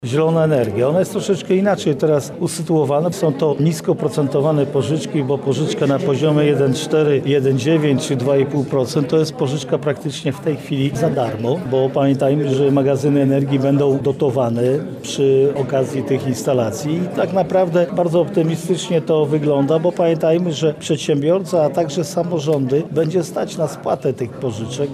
Jarosław Stawiarski – mówi Jarosław Stawiarski, marszałek województwa lubelskiego.